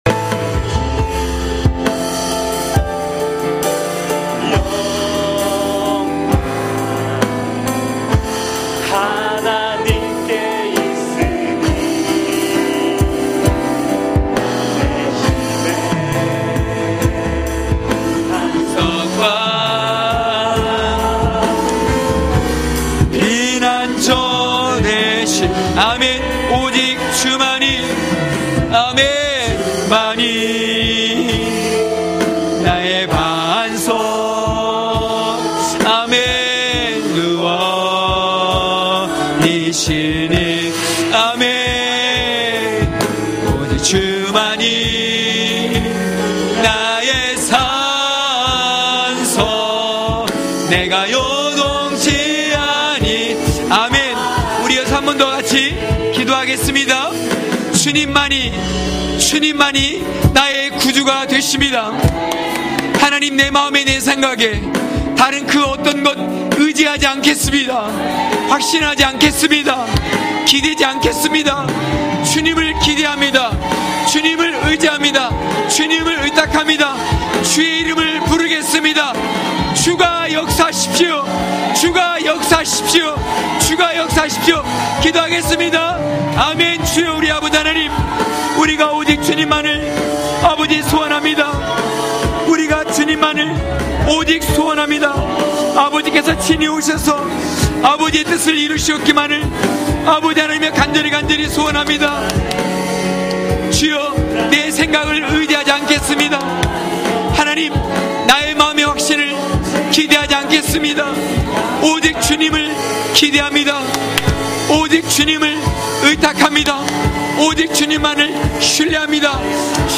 강해설교 - 13.경외함이 히브리인이다!!(느8장1~12절).mp3